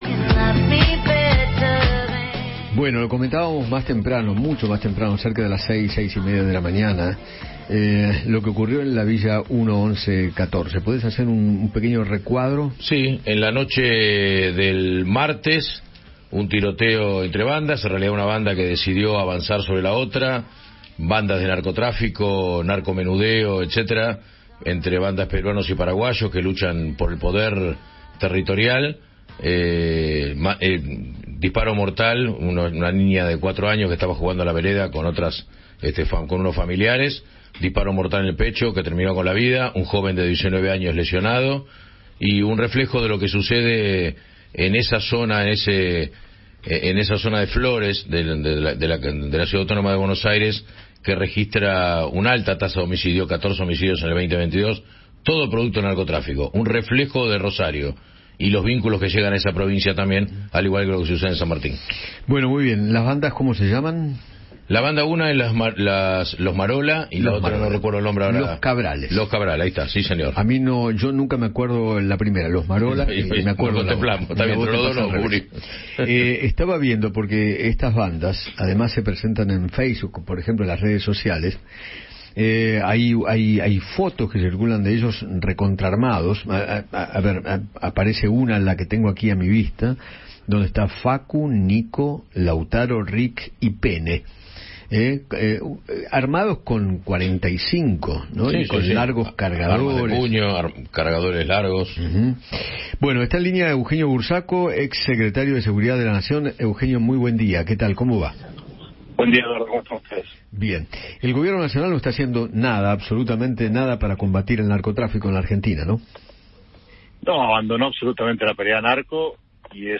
Eugenio Burzaco, ex secretario de Seguridad de la Nación, habló con Eduardo Feinmann sobre la balacera que hubo en el Bajo Flores, donde mataron a una nena de 4 años, y lo equiparó con lo que sucede en Rosario.